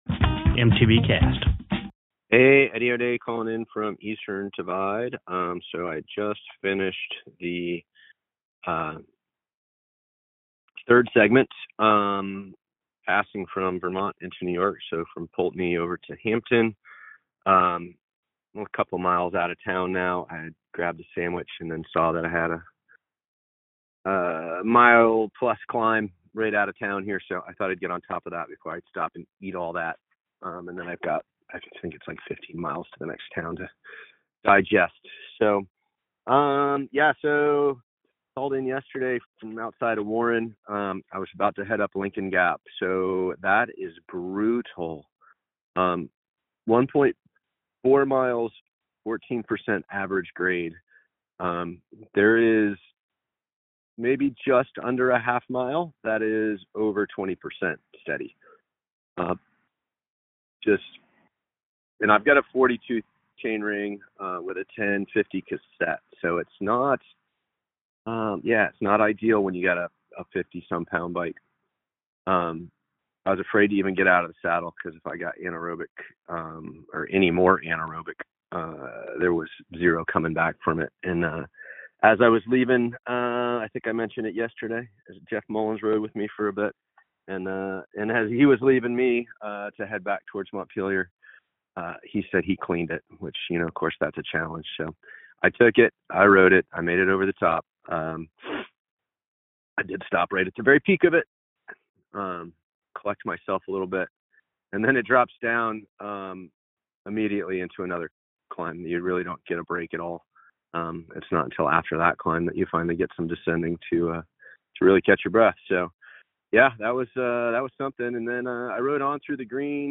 called in from Hampton!